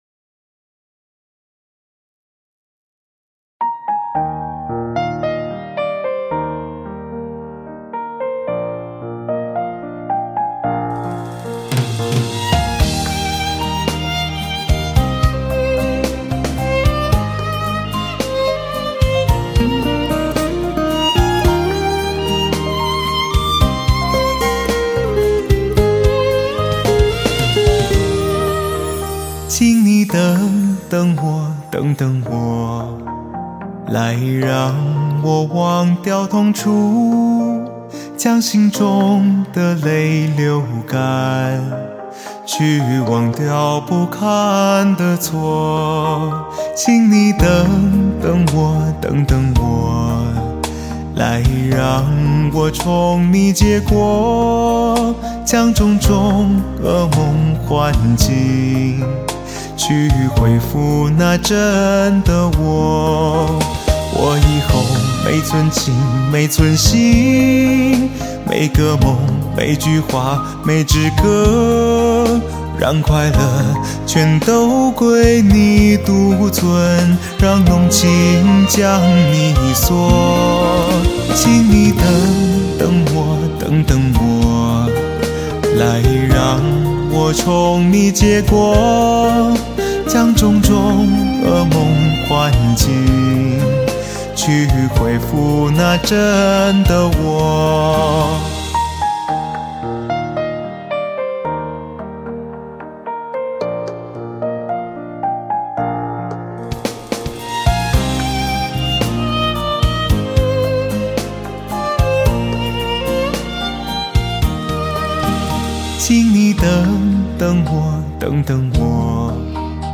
★人声磁性无比，高音明亮，中音饱满，低音浑厚有力，感情到位！